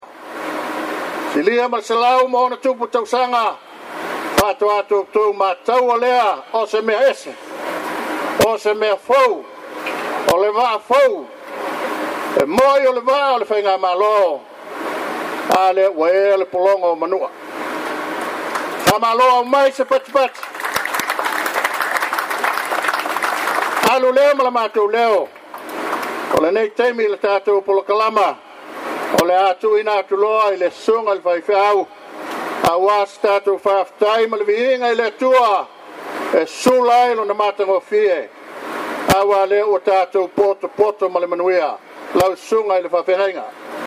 It’s been an uplifting, emotional and noisy morning at the main dock for the arrival of the long awaited MV Manu’atele.